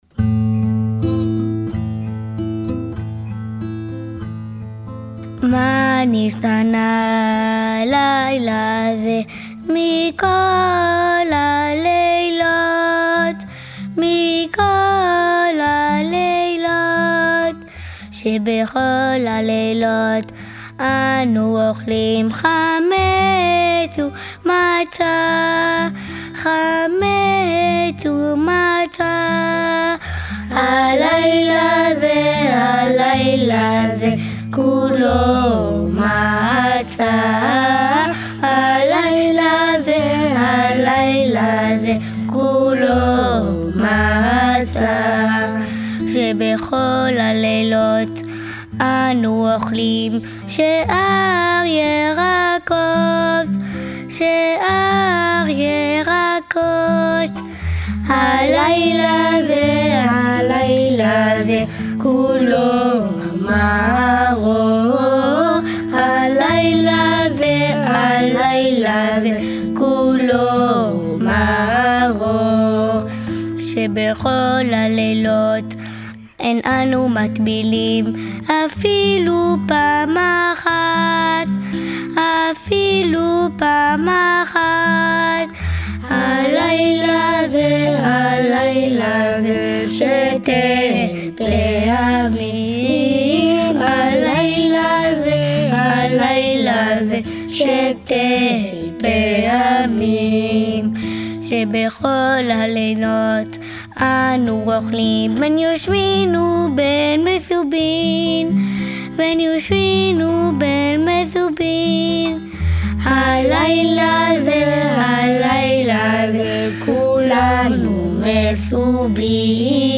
« Ma nichtana » – ou Les Quatre Questions – est chanté pendant le séder de Pessa’h, traditionnellement par les enfants.
Audio Enfants: